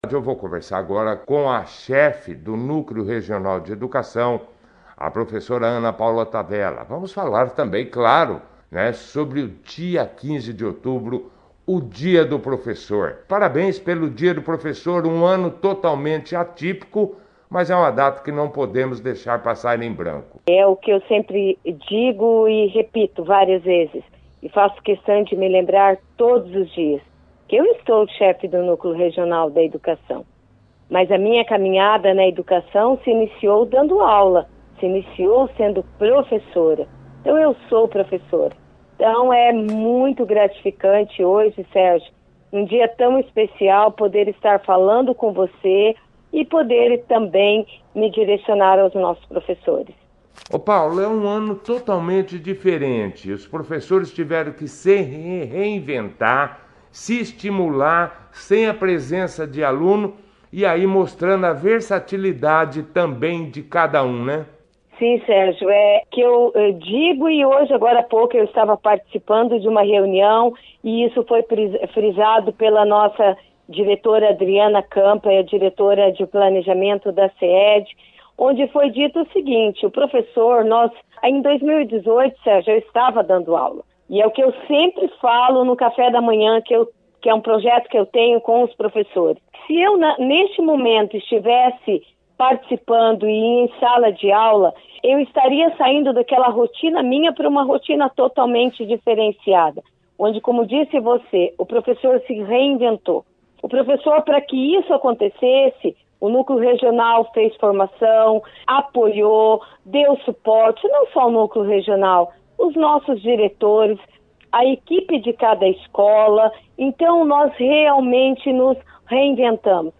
A 2ª edição do jornal Operação Cidade desta quinta-feira, 15/10, trouxe uma entrevista